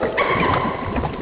OVEN.AU